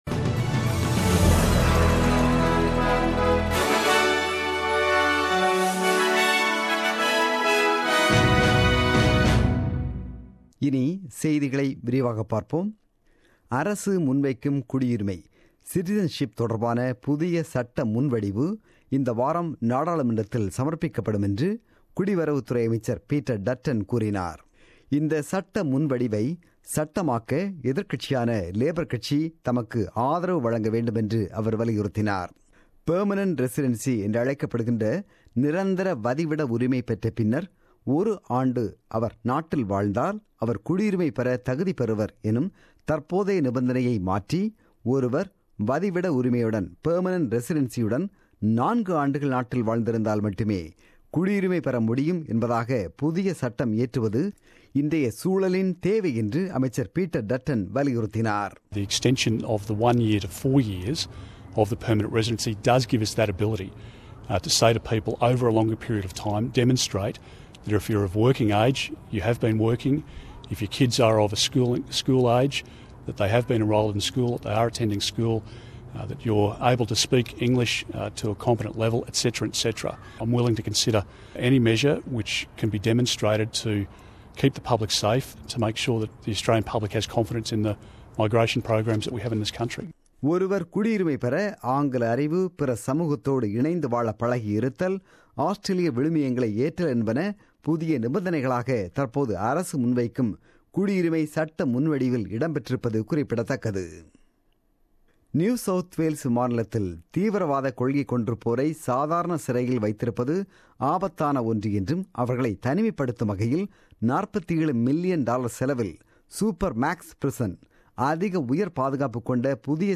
The news bulletin broadcasted on 11 June 2017 at 8pm.